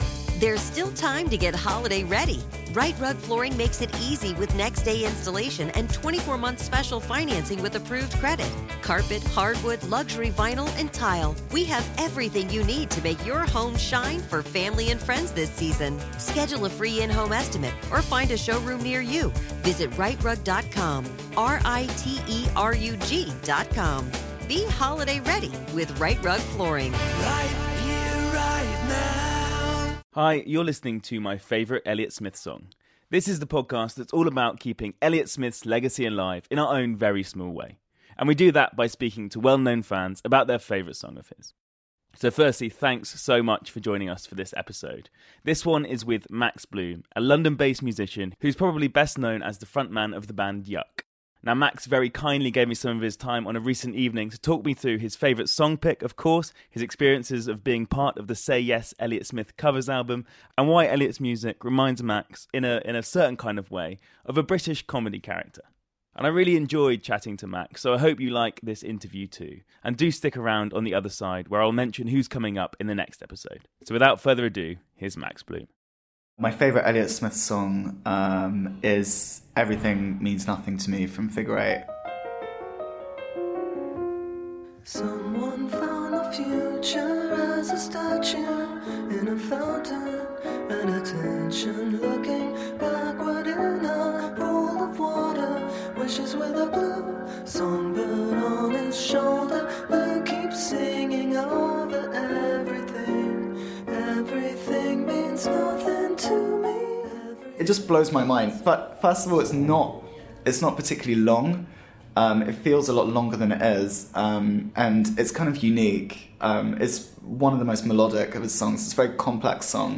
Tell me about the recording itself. This interview was recorded in London in June 2018.